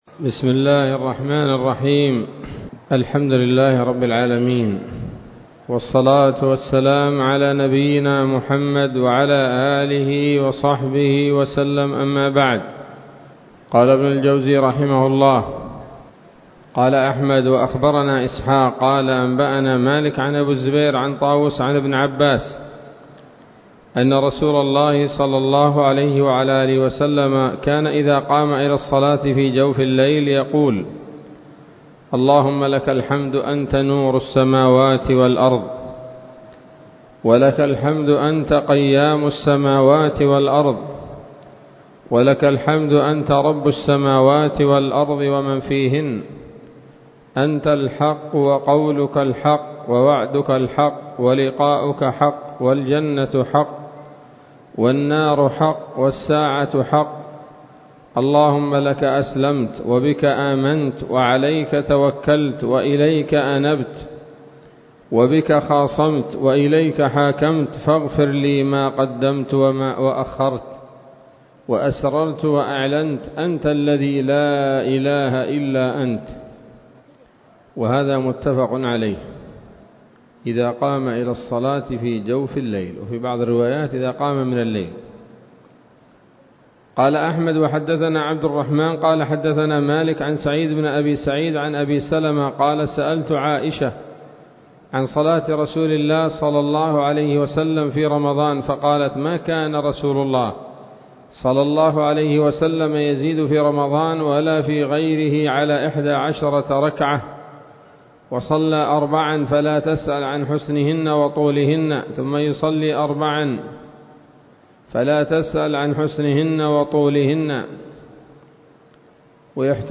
الدرس الحادي عشر من كتاب "قيام الليل" لابن الجوزي رحمه الله تعالى